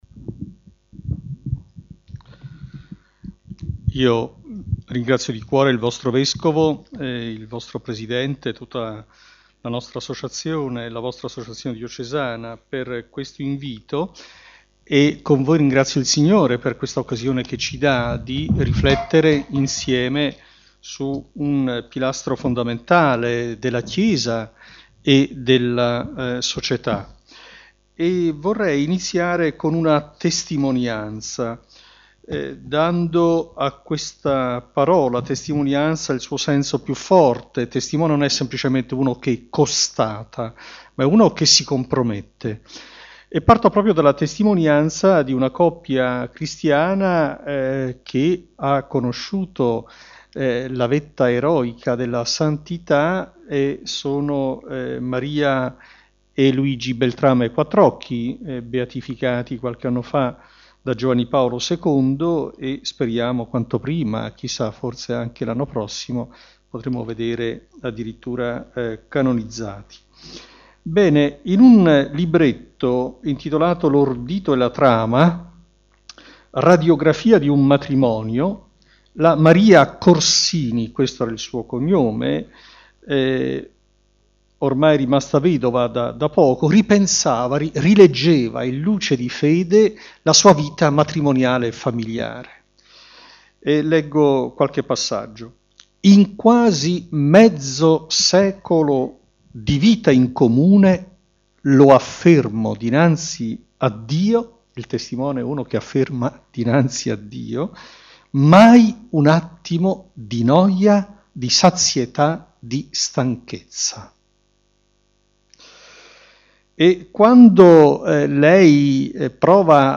Convegno diocesano sulla Chiesa famiglia di famiglie di Mons. Lambiasi vescovo di Rimini
Intervento di SE Mons Lambiasi - Assistente Generale Nazionale di Azione Cattolica